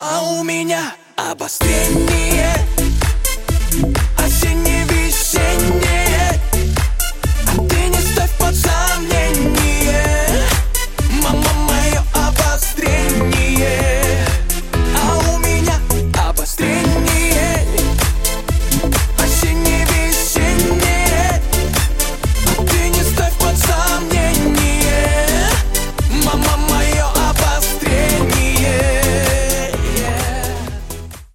танцевальная